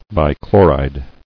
[bi·chlo·ride]